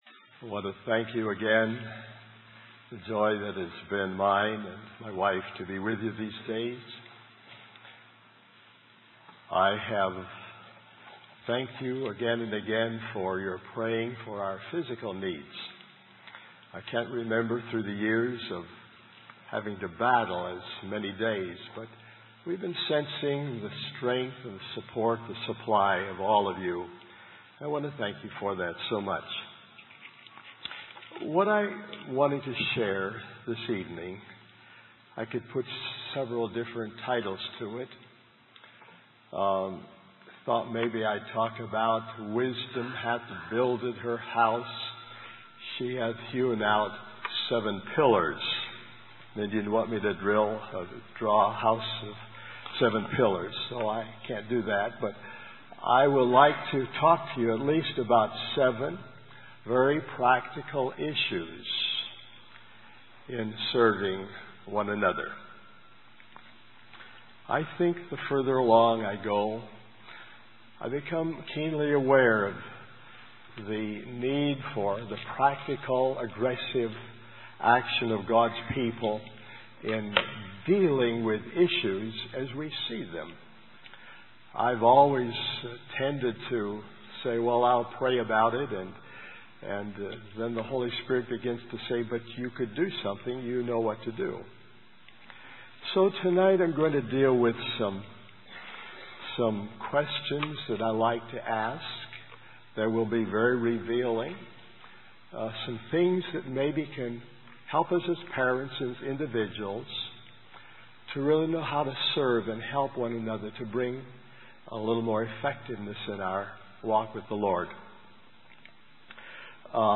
In this sermon, the speaker encourages the audience to recognize the principles behind their actions and decisions. They pray for the audience to move beyond simply having gifts and talents, but to also allow brokenness and consecration in their lives.